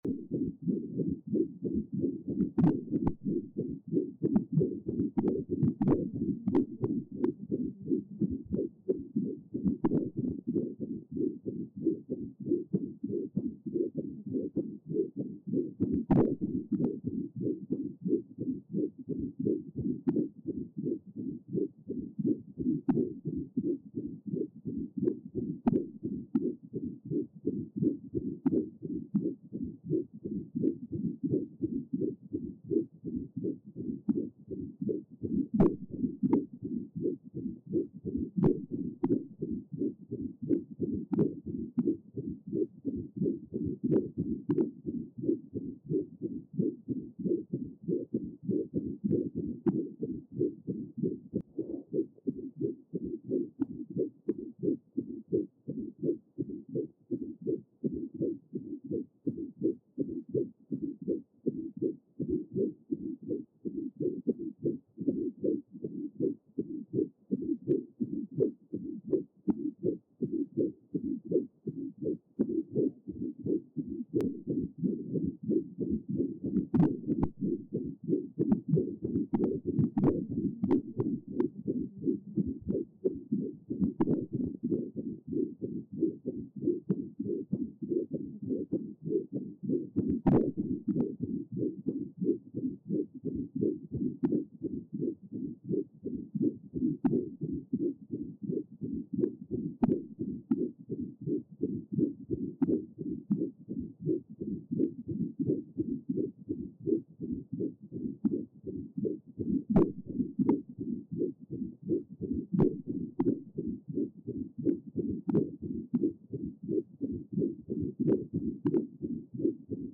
Die Hördatei, welche einen sich wiederholenden Herzschlag wiedergab, schuf eine besondere Raumatmosphäre.
herz-mixed.mp3